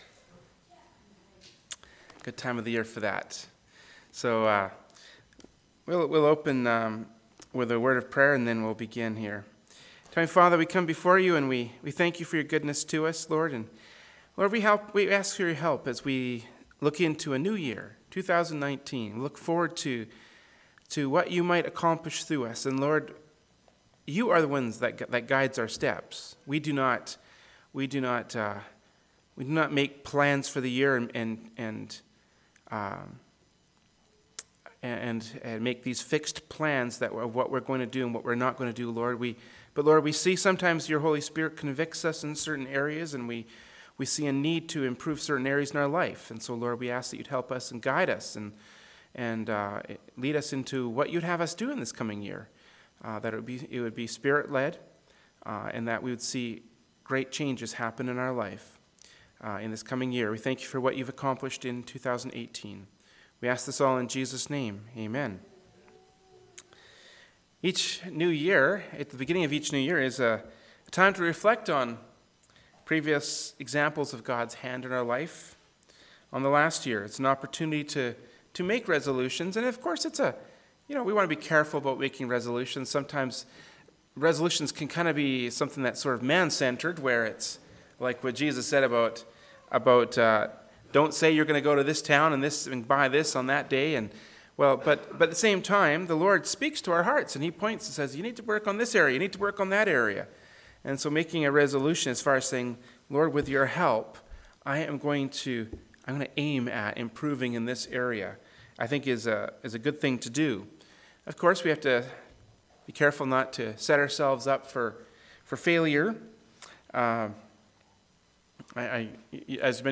“New Year Resolutions” from Wednesday Evening Service by Berean Baptist Church.
2nd Corinthians 3:18 Service Type: Wednesday Evening Service “New Year Resolutions” from Wednesday Evening Service by Berean Baptist Church. Topics: New Year , Resolutions « Psalms 119:17-24